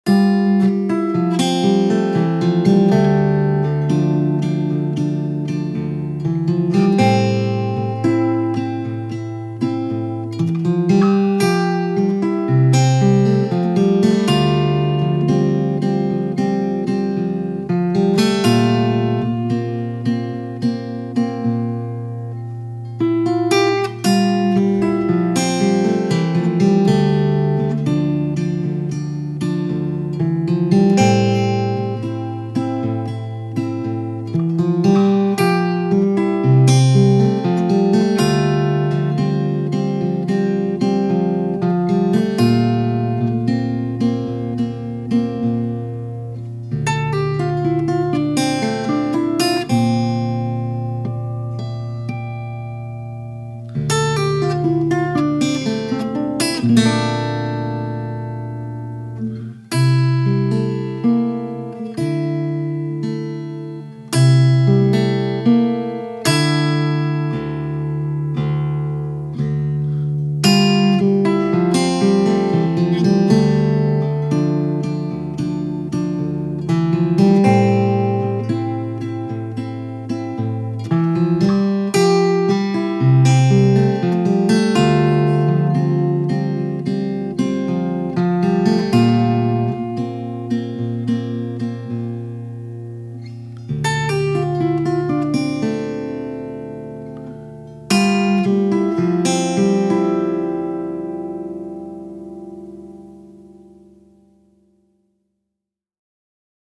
A short solo acoustic guitar tune is my latest entry in the Guitarist Home Recording Collective, one of my favourite mini-communities on the Net.
The piece was really suggested by the tuning, as often happens… experimenting with odd tunings, I somehow arrived at D Bb C G A C (low to high) and the tune just tumbled out.